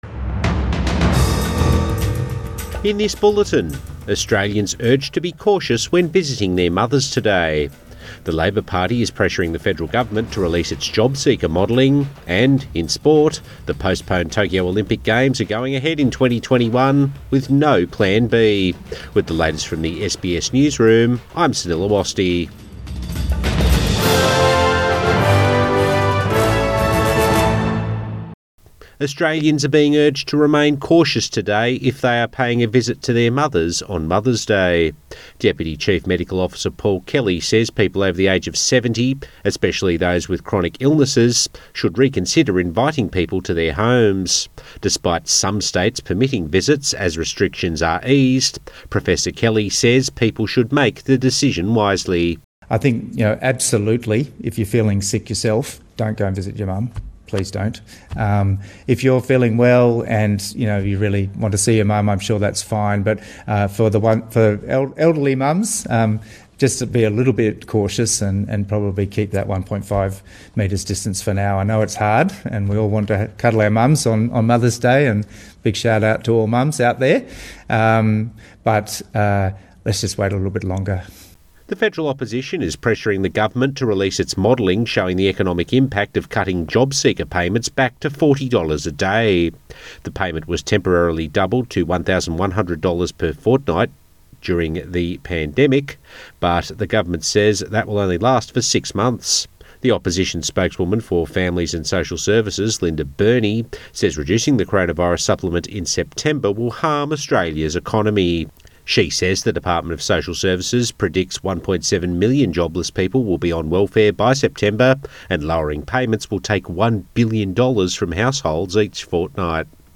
AM Bulletin 10 May 2020